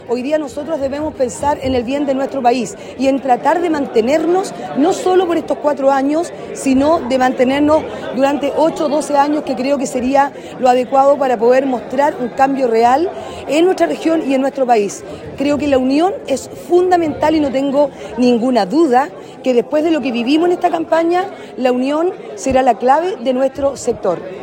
La diputada independiente en cupo de la UDI, Marlén Pérez, al ser consultada sobre el rol que debe asumir la derecha tras el triunfo de José Antonio Kast, dijo que se requiere de trabajo en unidad para así proyectar al sector por más de una década.